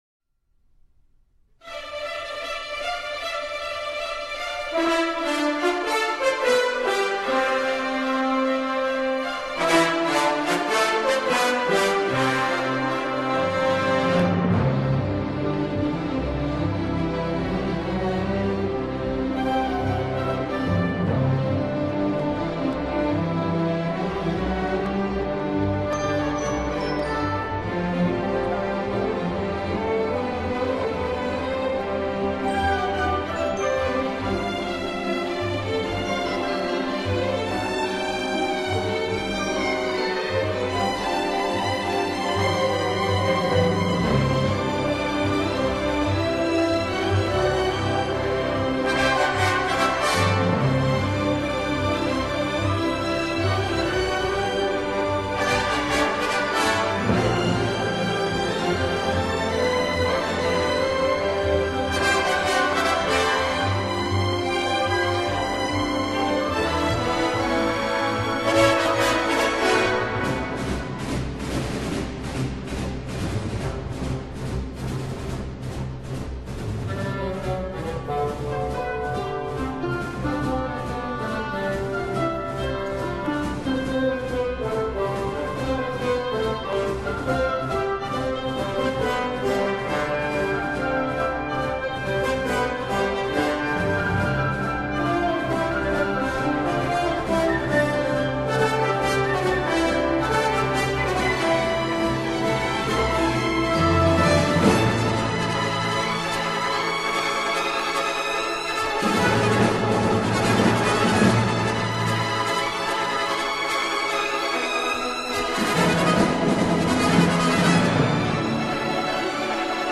Category: Radio   Right: Personal